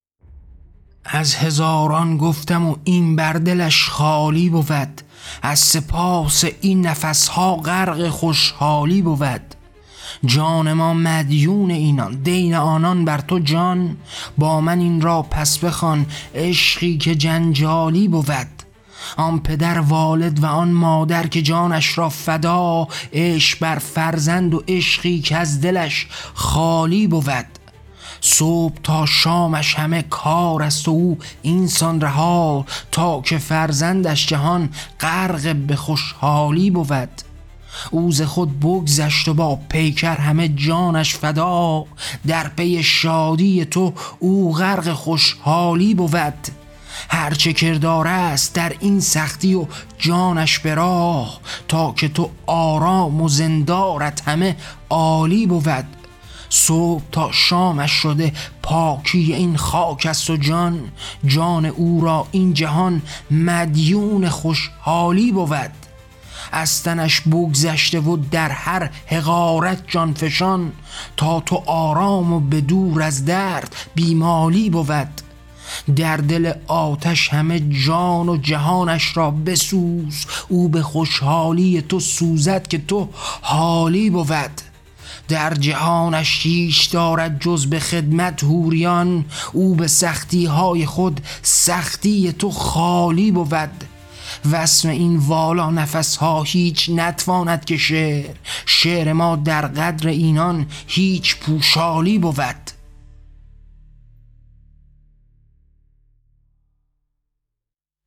کتاب طغیان؛ شعرهای صوتی؛ از جان گذشتگان: ستایش ایثار والدین و دینِ آگاهی